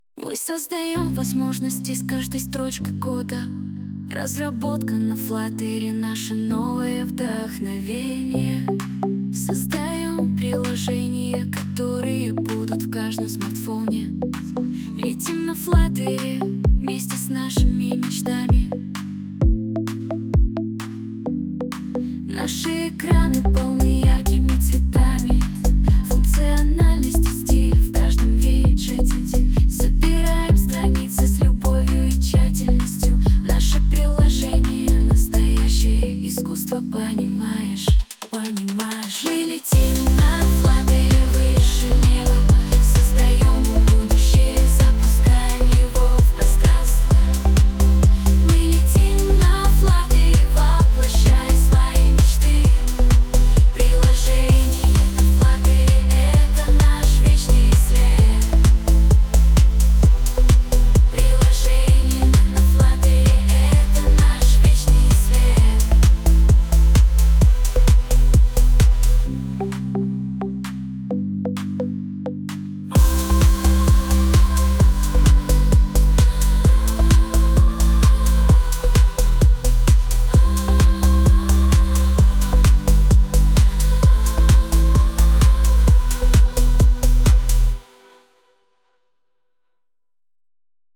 Вот такую песенку запилила новая сетка Suno про Flutter.